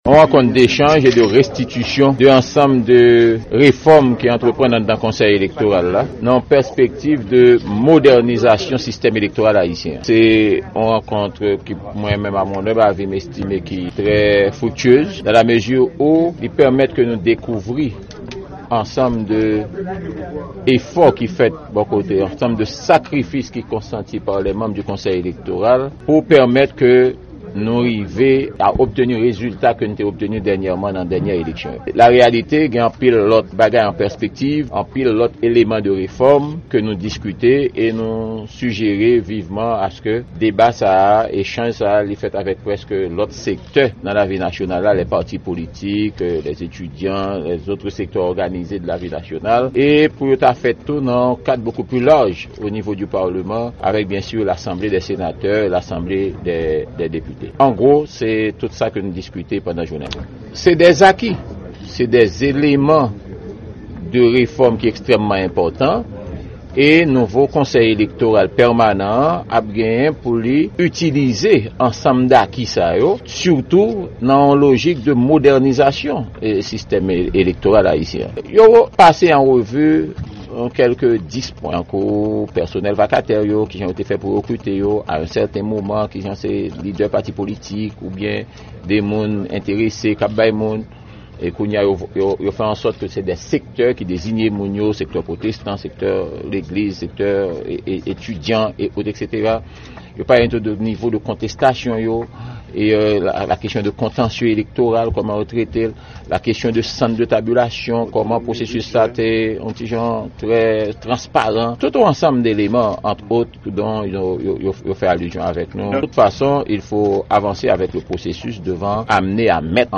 Deklarasyon Prezidan Sena a, Joseph Lambert, sou rankont li ak manm KEP yo